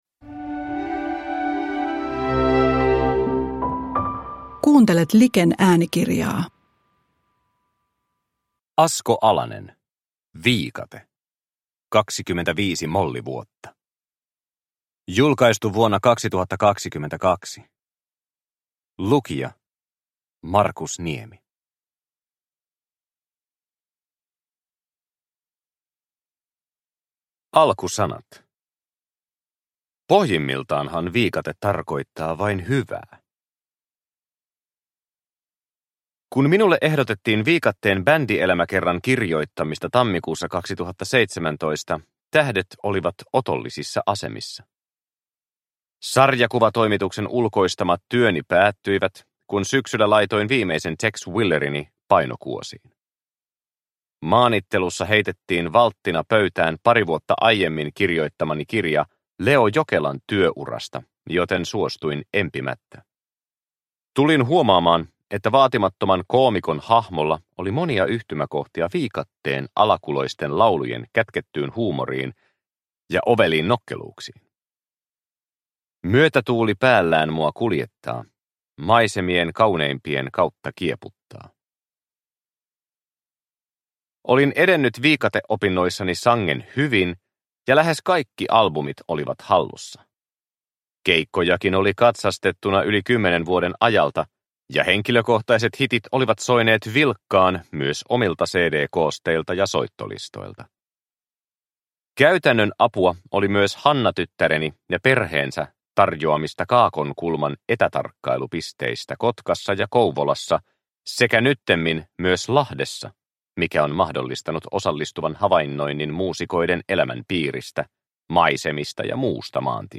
Viikate – Ljudbok – Laddas ner